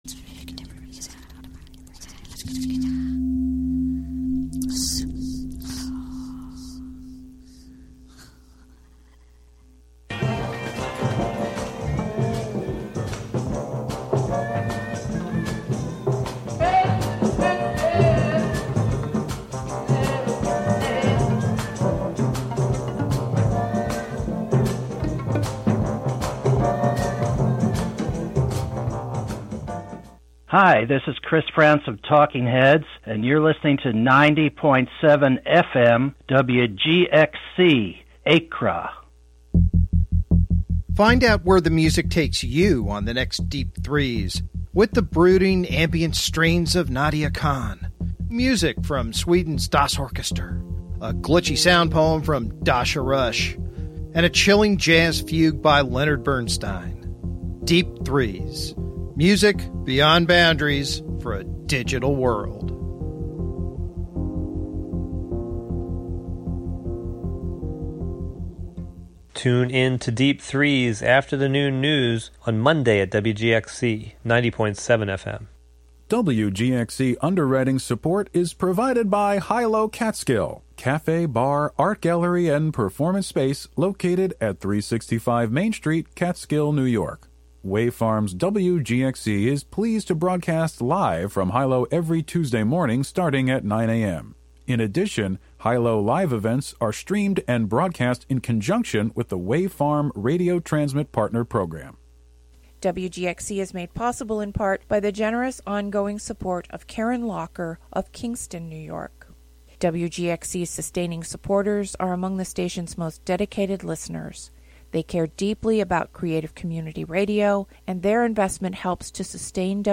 5am Monthly program featuring music and interviews.